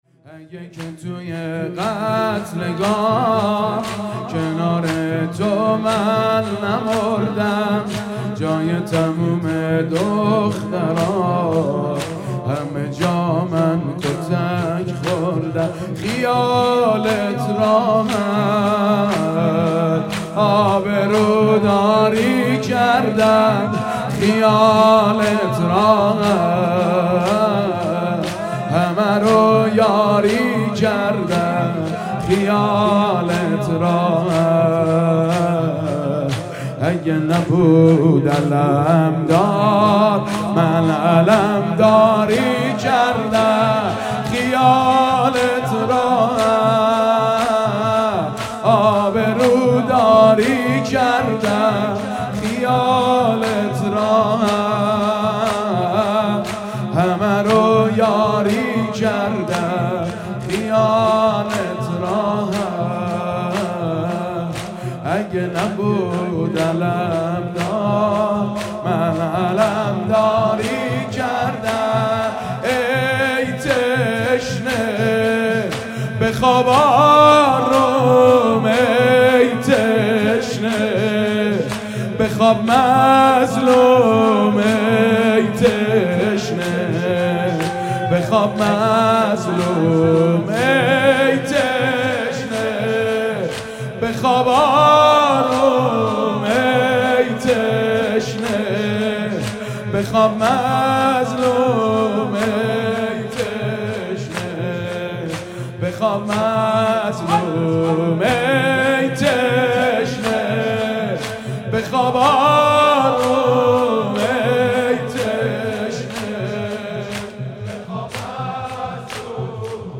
مداحی زیبای «توی قتلگاه» با نوای حنیف طاهری ویژه اربعین 1396.